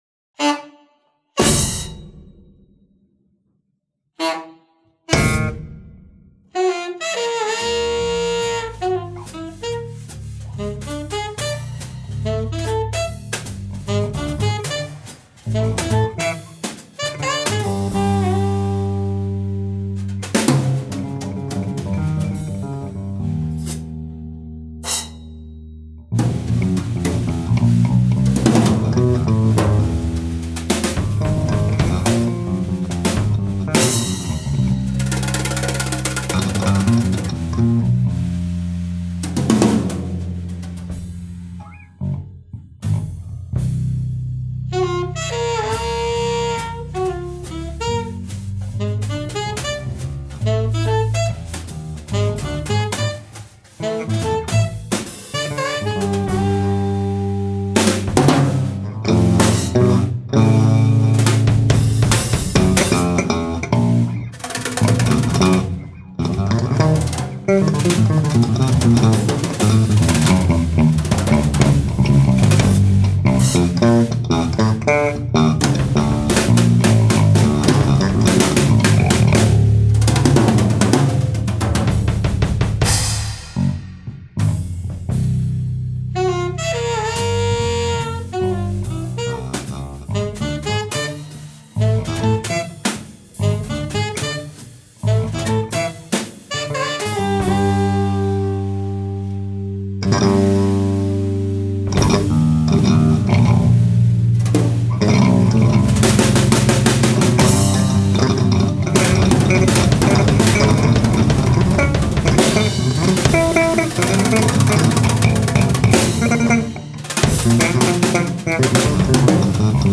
Genre: Jazz.